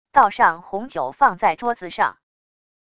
倒上红酒放在桌子上wav音频